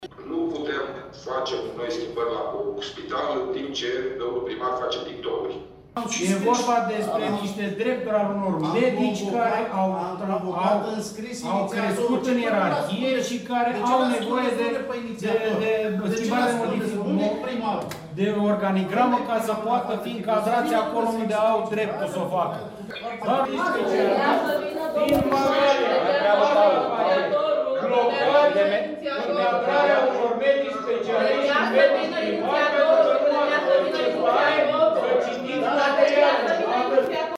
AUDIO | Discuții aprinse în ședința extraordinară de Consiliu Local din Mangalia
Ședința extraordinară s-a desfășurat în sistem mixt.